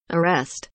arrest kelimesinin anlamı, resimli anlatımı ve sesli okunuşu